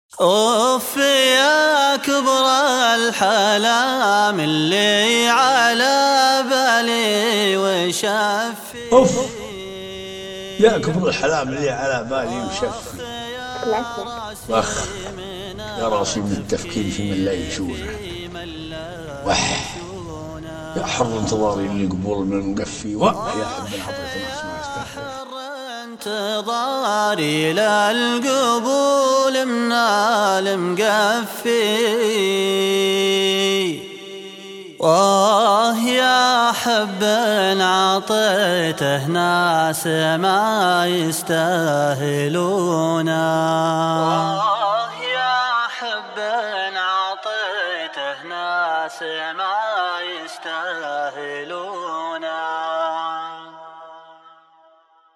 شيلة + قصيدة